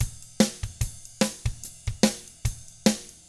146ROCK T3-R.wav